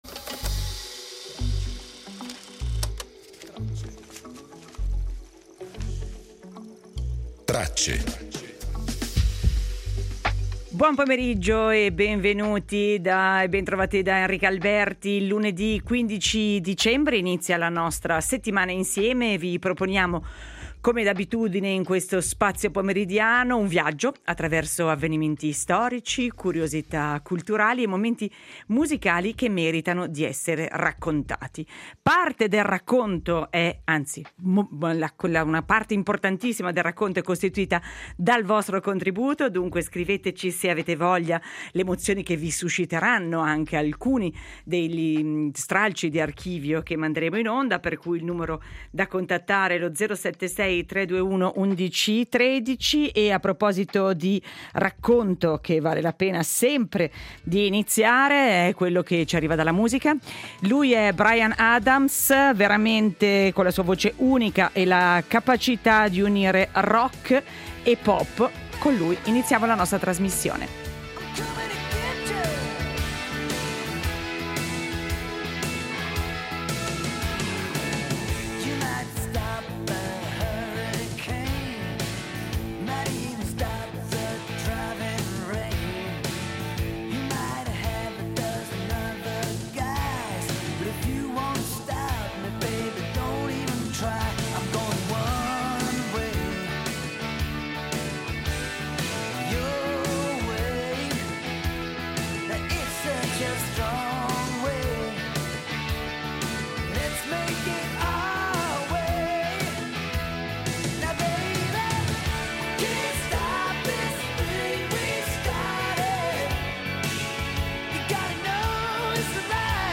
ZUMA Press Wire Contenuto audio Disponibile su Scarica La voce di un uomo che ha rivoluzionato il mondo dell’intrattenimento per ricordarlo nel giorno della sua scomparsa il 15 dicembre 1966, un Walt Disney intercettato a Parigi nel 1951. I compleanni di Nerone e di Gustav Eiffel e la chiusura dei sindacati nel Cile di Pinochet del 1973.